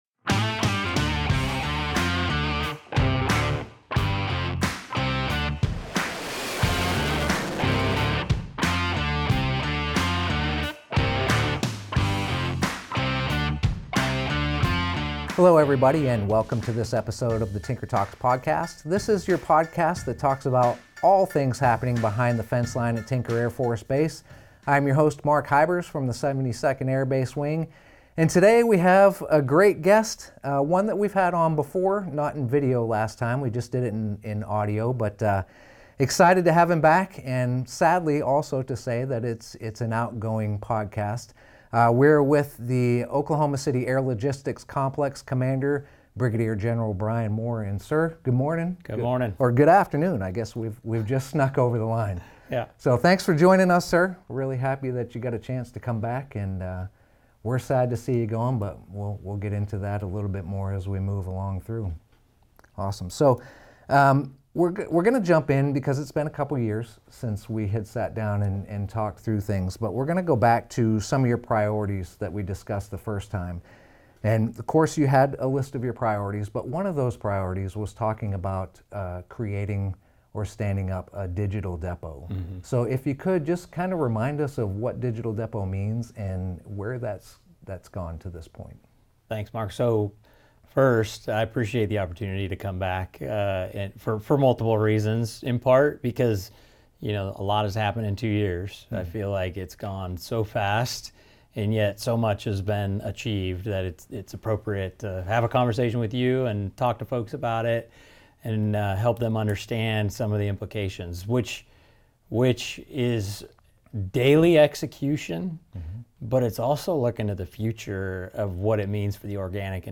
Tinker Talks Podcast: A Farewell Conversation with Brig. Gen. Brian Moore by Tinker Talks